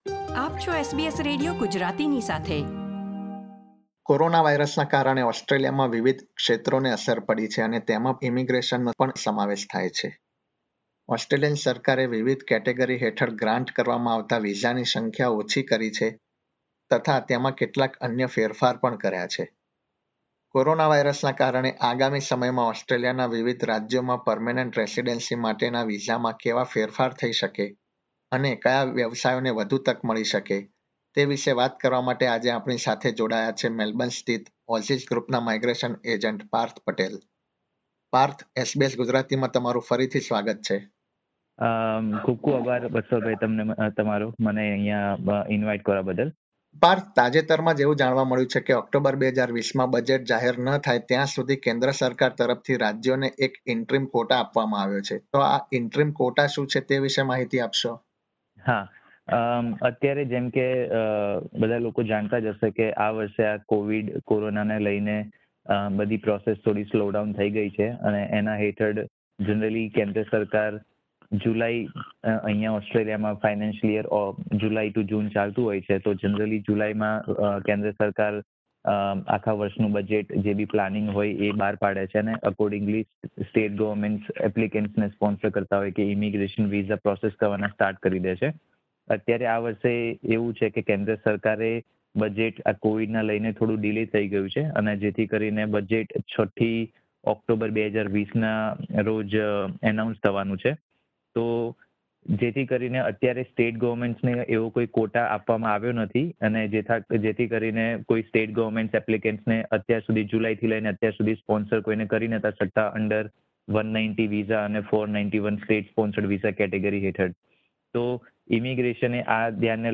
gujarati_2808_migrationinterview.mp3